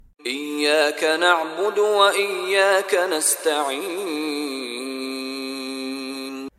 Contoh Bacaan dari Sheikh Mishary Rashid Al-Afasy
DIPANJANGKAN huruf Mad dengan 2 harakat